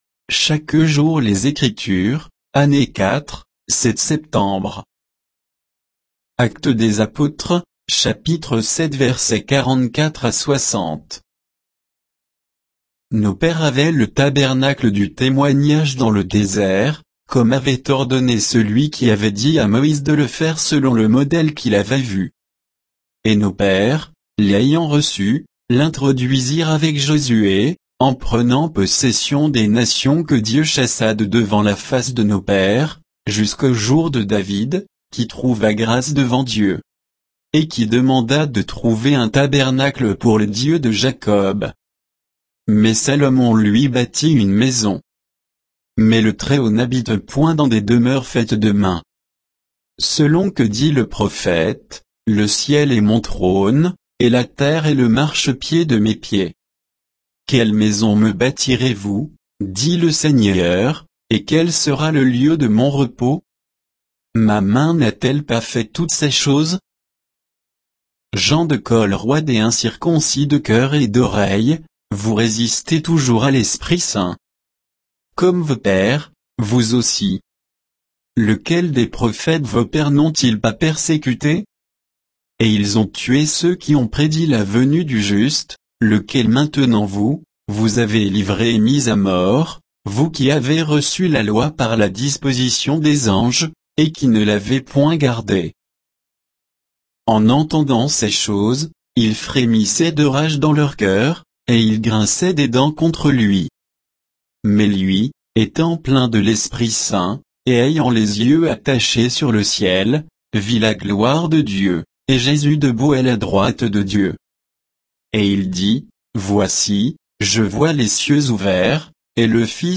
Méditation quoditienne de Chaque jour les Écritures sur Actes 7